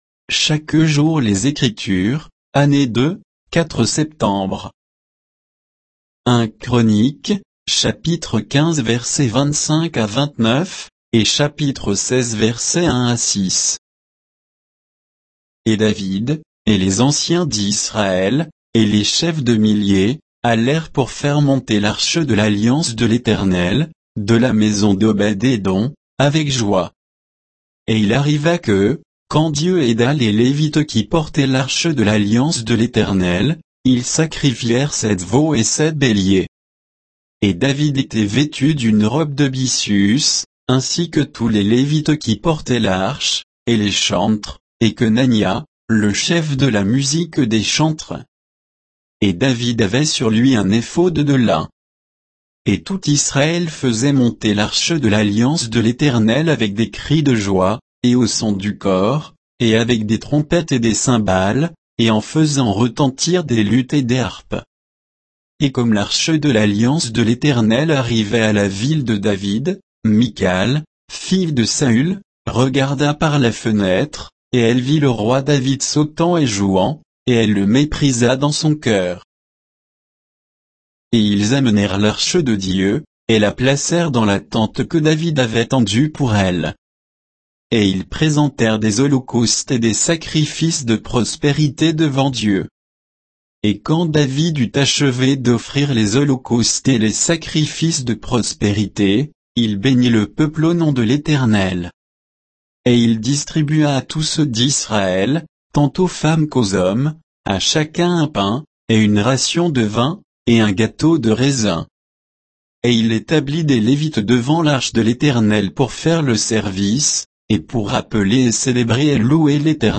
Méditation quoditienne de Chaque jour les Écritures sur 1 Chroniques 15